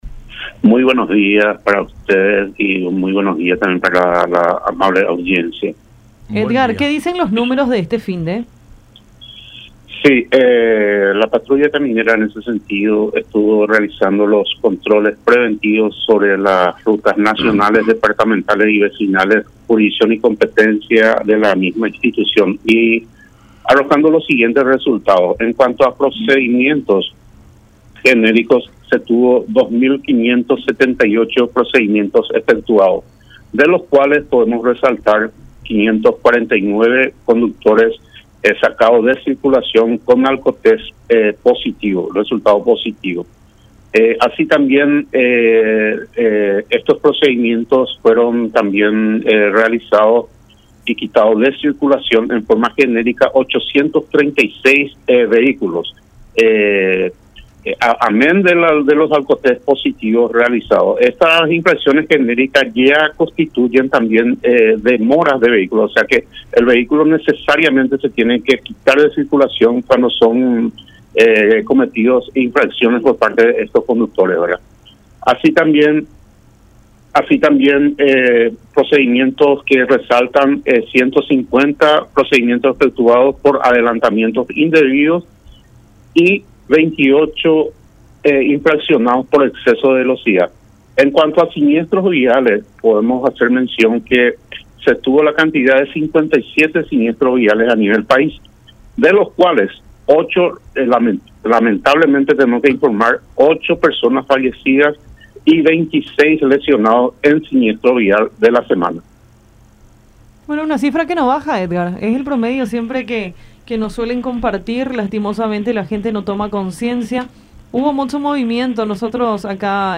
en charla con Enfoque 800 por La Unión.